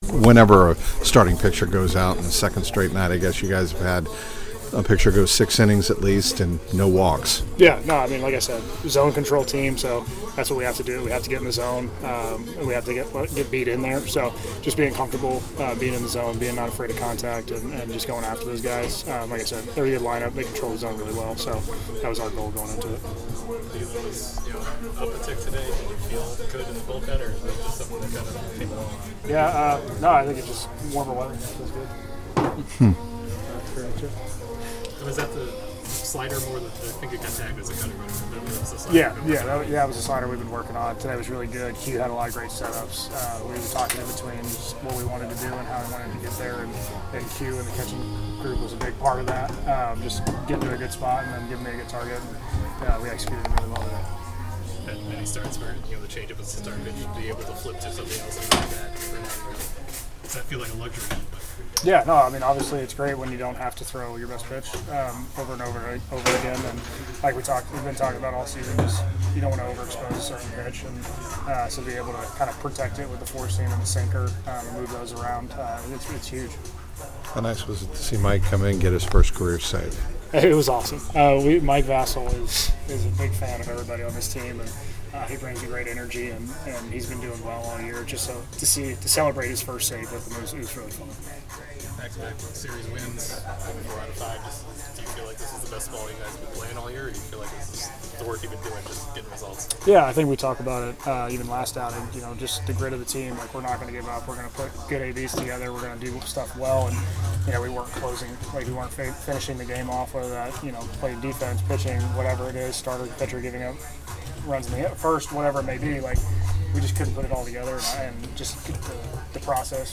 Davis Martin Chicago White Sox RHP Postgame Locker Room winning pitcher in 4-2 win over Reds on May 14, 2025